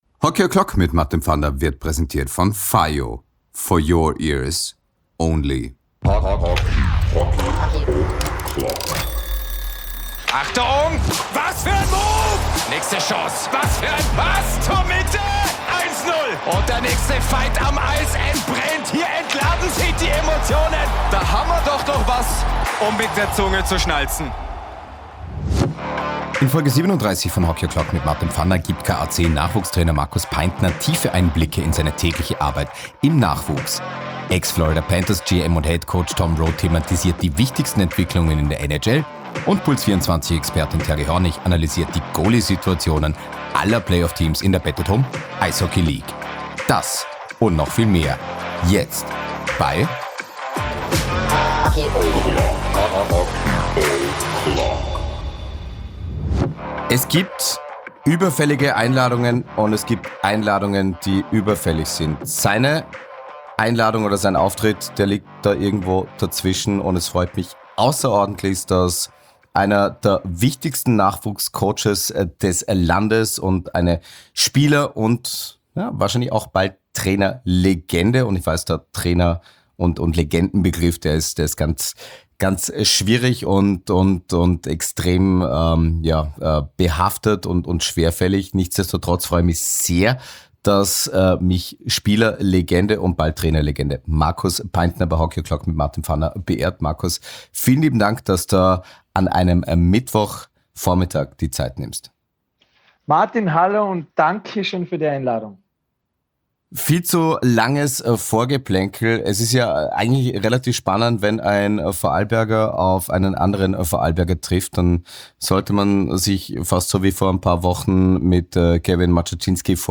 win2day Hockey O'Clock ist der Eishockey-Podcast über das österreichische Eishockey, die win2day ICE Hockey League aber auch internationale Eishockey-Ligen. In ausführlichen Interviews mit Tiefgang kommen Spielerinnen und Spieler, Trainerinnen und Trainer sowie Funktionärinnen und Funktionäre im nationalen und internationalen Umfeld zu Wort.